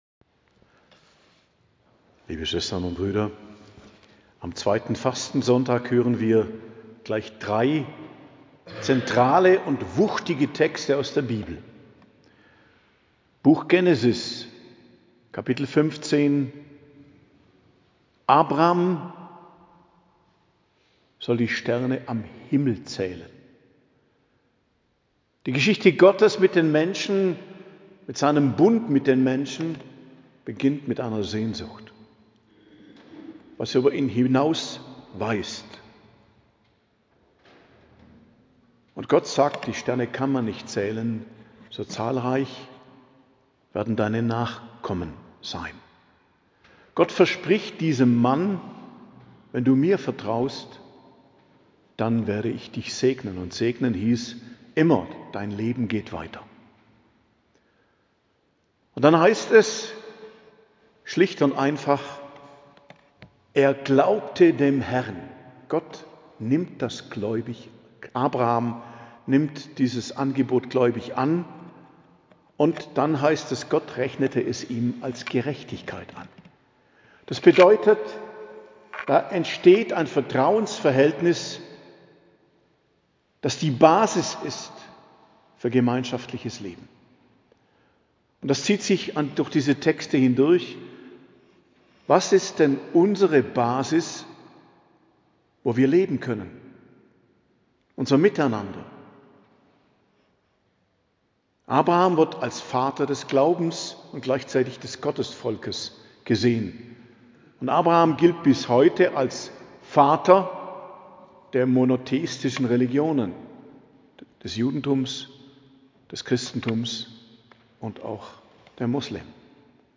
Predigt zum Zweiten Fastensonntag, 16.03.2025 ~ Geistliches Zentrum Kloster Heiligkreuztal Podcast